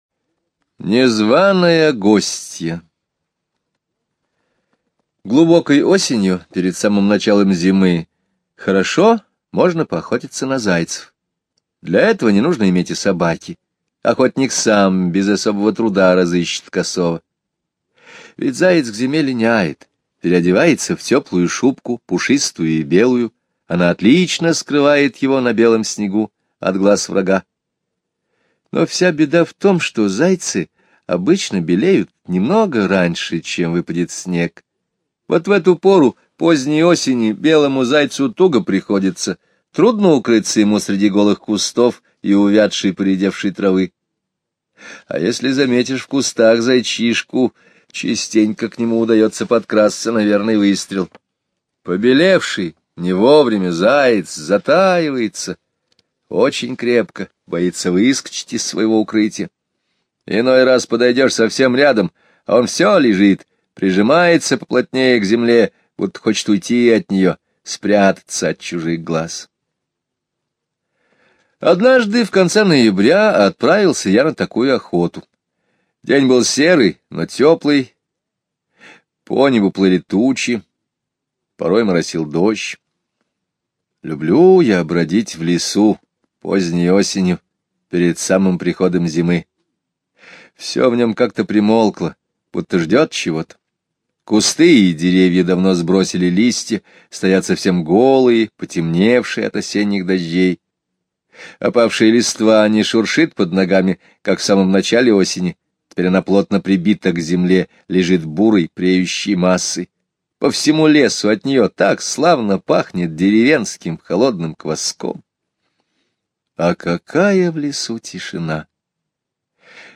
Незваная гостья - Скребицкий - слушать рассказ онлайн